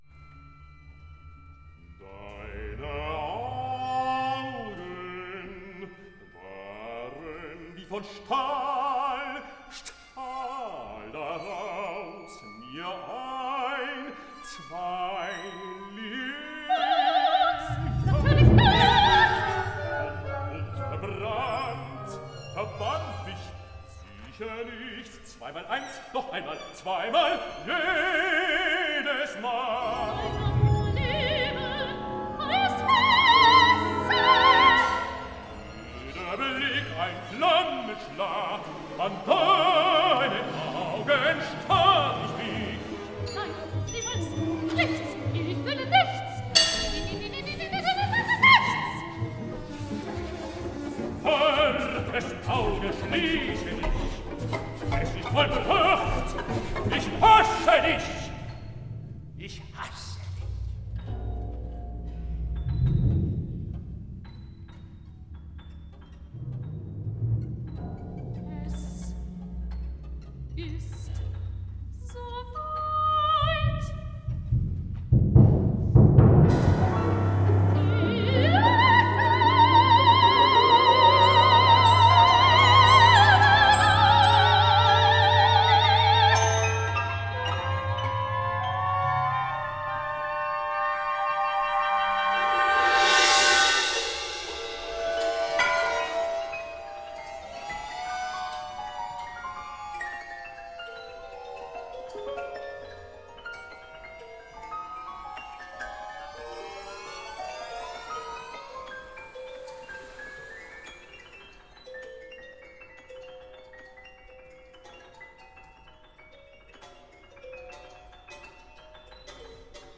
for soprano, baritone and ensemble (2013)